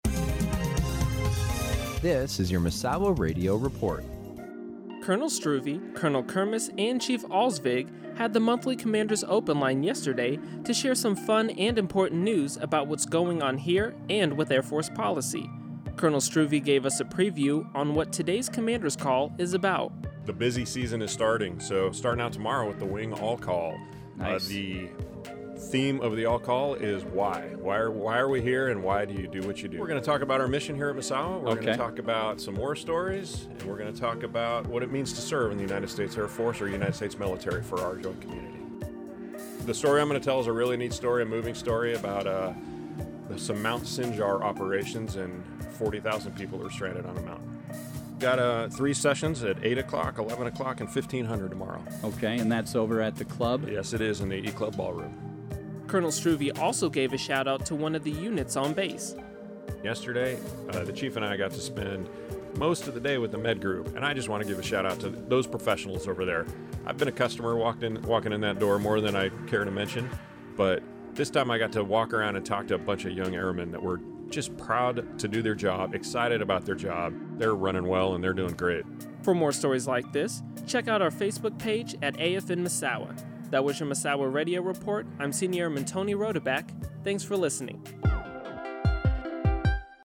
Commanders Open Line Misawa Radio Report 3 Aug 18